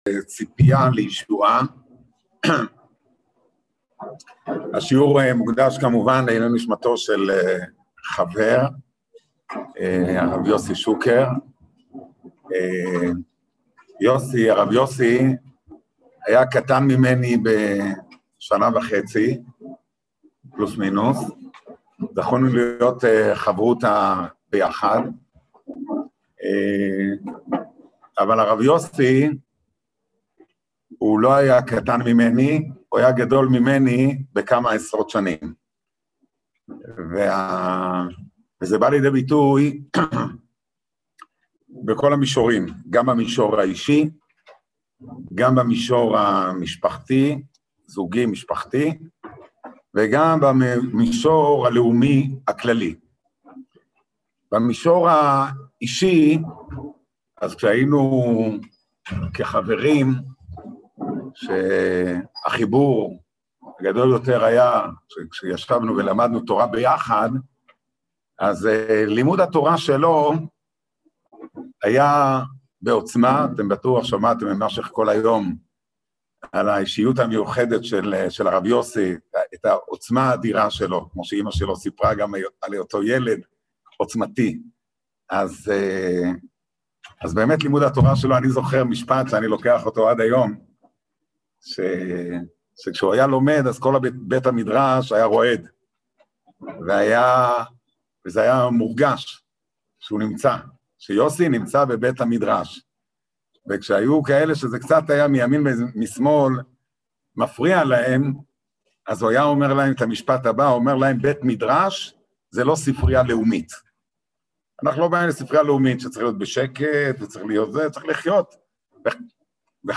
ציפית לישועה? | יום עיון לקראת יום העצמאות תשפ"א | מדרשת בינת